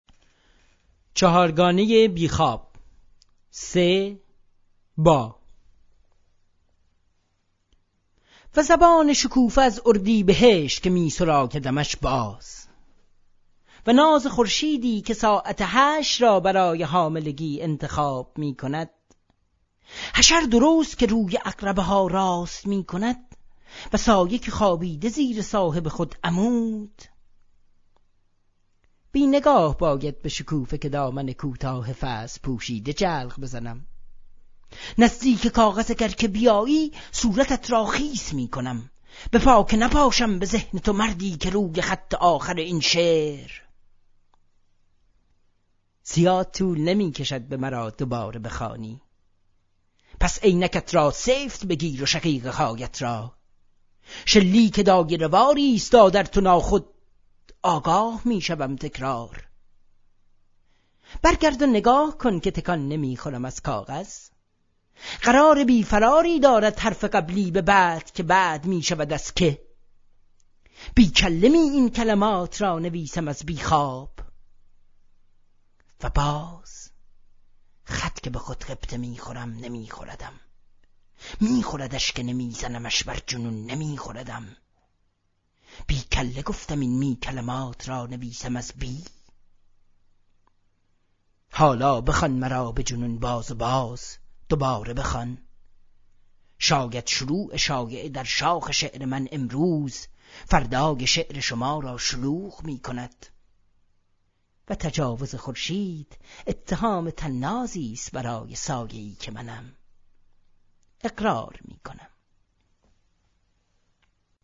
صدای شاعر